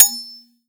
Metal Clank 5
clang clank ding hit impact metal metallic ping sound effect free sound royalty free Sound Effects